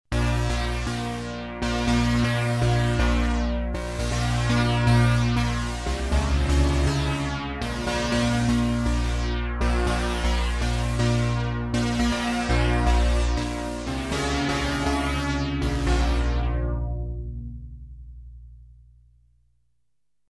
Elka Synthex
A synth basic Synthex patch is composed by 2 DCO oscillators + 1 white-pink noise generator.
stereo mode